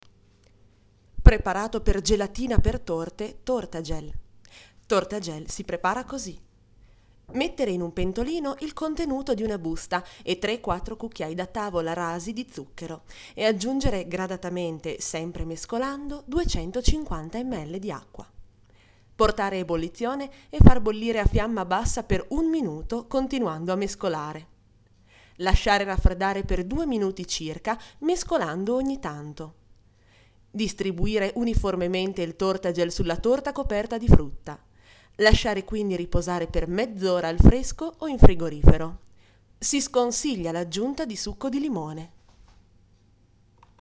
Kein Dialekt
Sprechprobe: Sonstiges (Muttersprache):